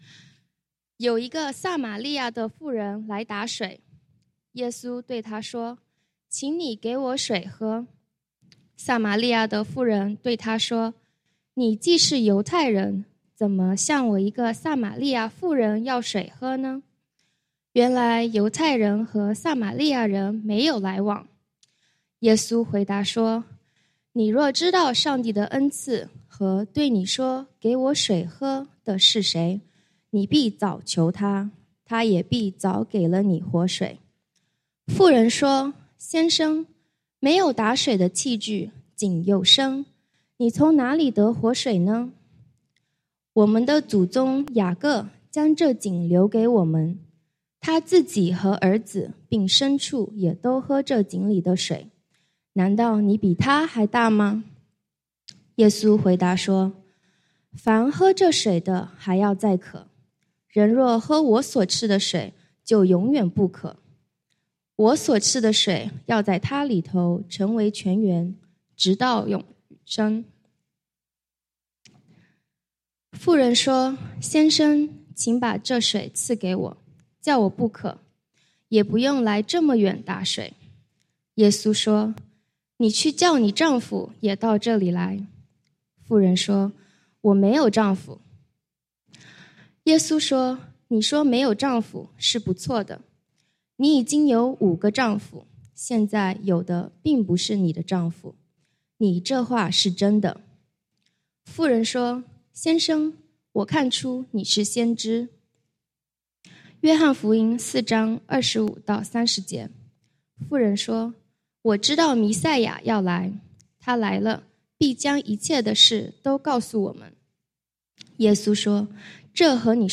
講道經文：約翰福音 John 4:7-19, 25-30, 39-42